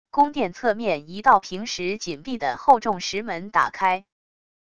宫殿侧面一道平时紧闭的厚重石门打开wav音频